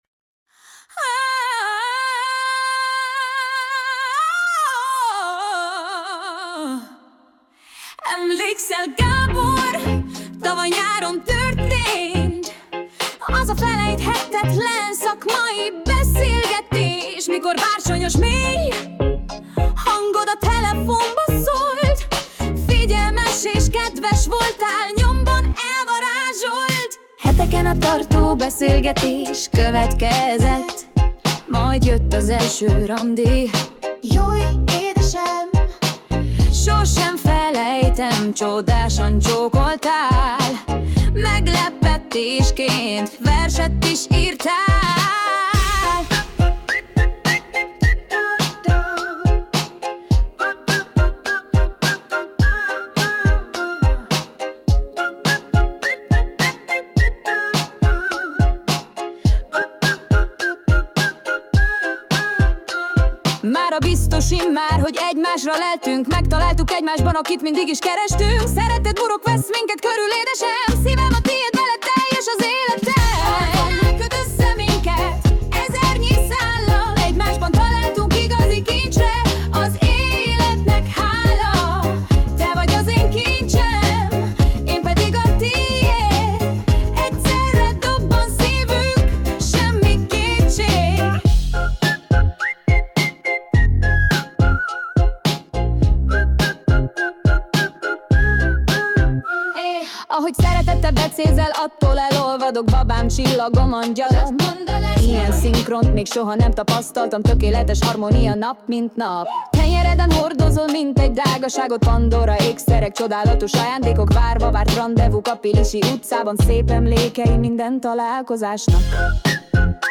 Pop - Szülinapra